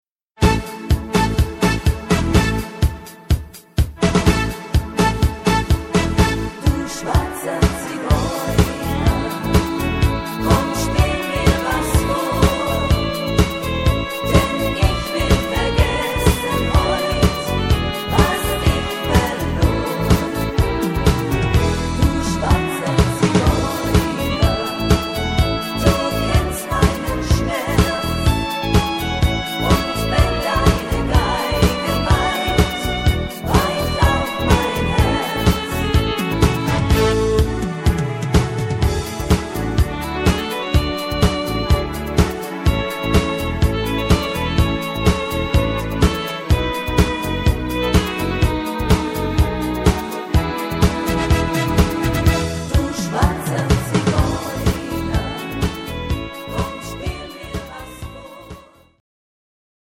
Rhythmus  Disco
Art  Deutsch, Schlager 90er